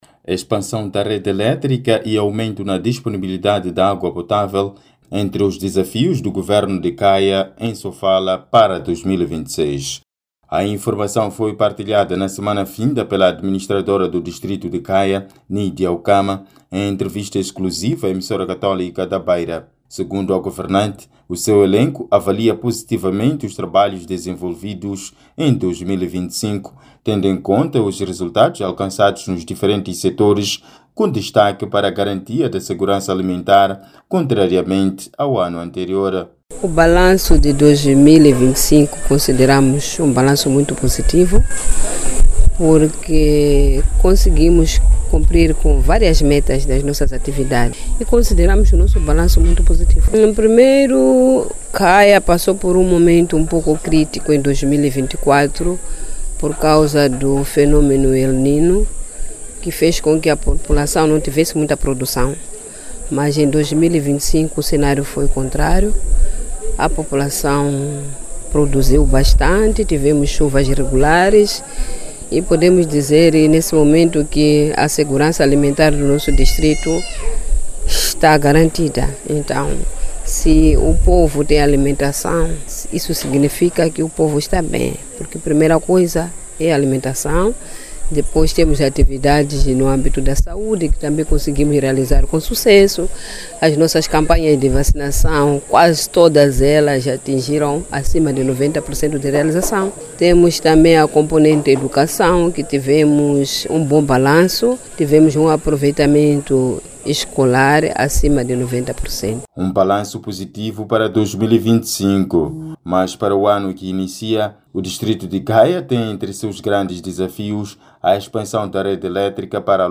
A informação foi partilhada na semana finda pela Administradora do distrito de Caia, Nídia Ucama, em entrevista exclusiva à Emissora Católica da Beira.
A Administradora do distrito de Caia, Nídia Ucama, falava à nossa equipe no Posto Administrativo de Murraça, após ter participado na missa do encerramento do Ano Jubilar para a Zona pastoral Zambeze, onde também se deu a conhecer sobre a ereção da Diocese de Caia.